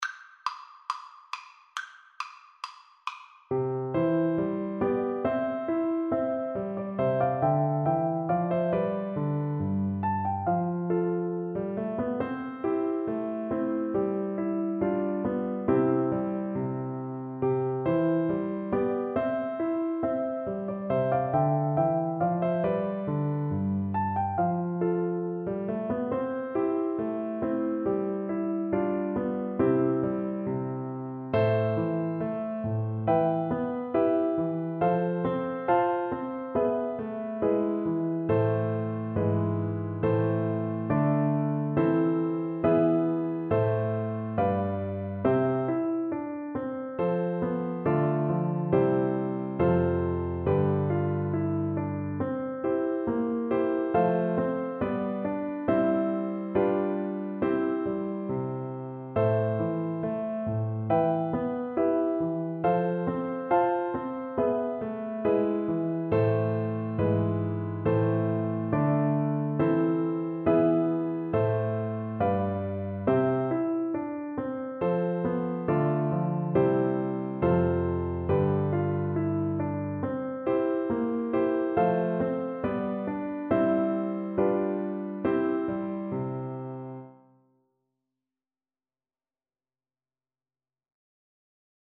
2/2 (View more 2/2 Music)
Steady two in a bar =c.69
Classical (View more Classical Flute Music)